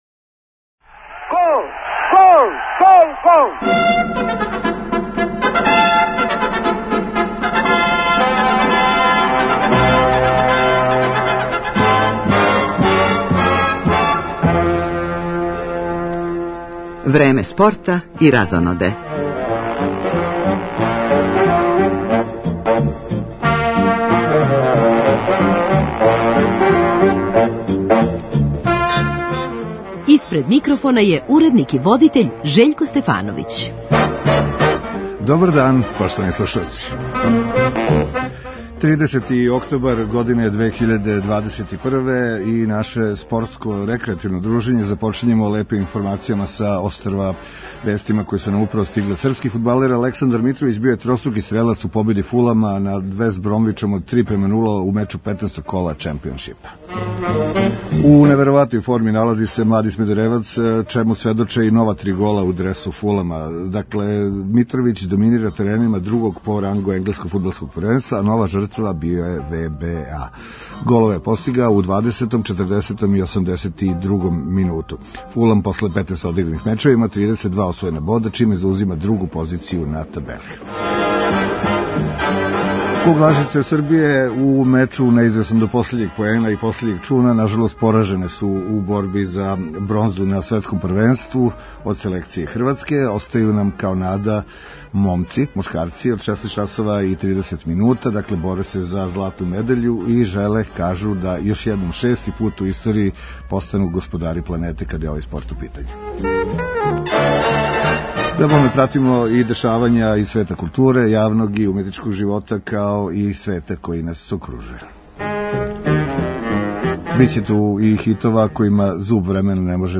Наредни меч наша селекција имаће 25. новембра против Бугарске. Данас је требало да буде одигран дерби Супер лиге између Црвене звезде и Спартака, али је одложен из добро знаних разлога, па ћемо имати фино фудбалско друштво у нашем студију.